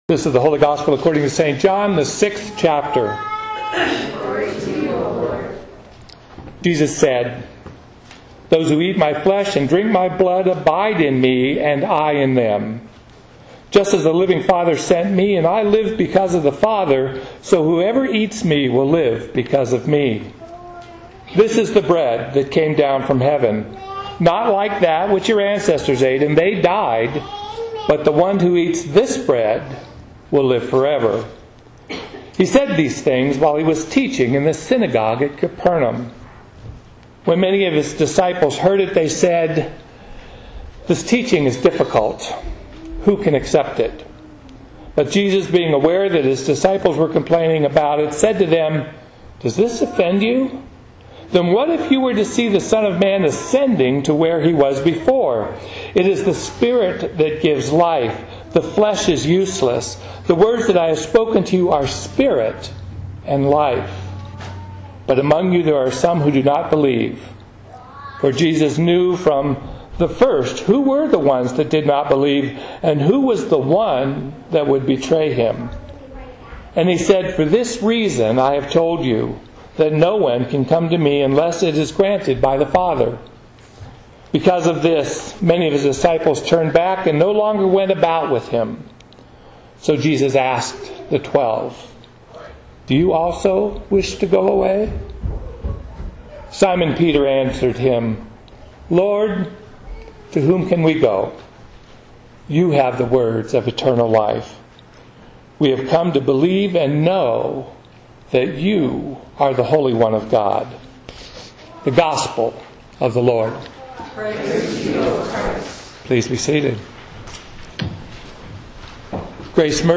"Choose" - Sermon 08-23-15